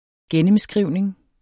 Udtale [ ˈgεnəmˌsgʁiwˀneŋ ]